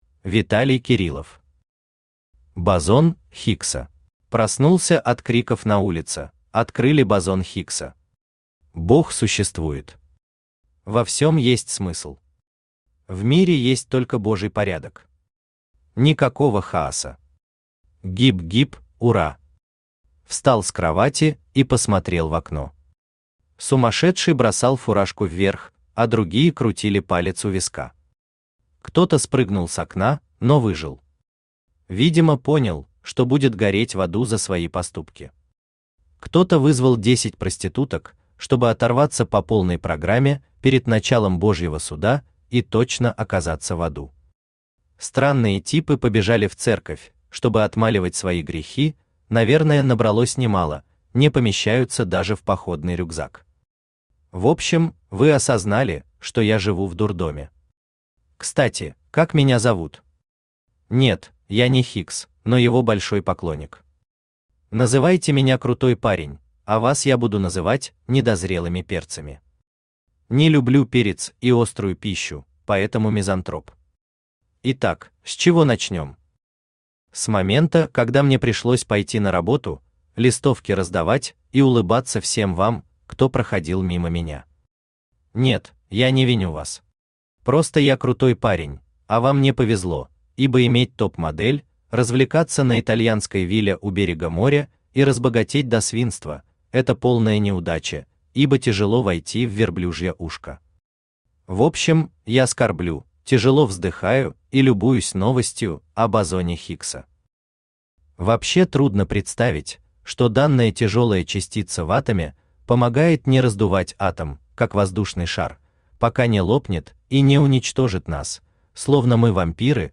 Аудиокнига Бозон Хиггса | Библиотека аудиокниг
Aудиокнига Бозон Хиггса Автор Виталий Александрович Кириллов Читает аудиокнигу Авточтец ЛитРес.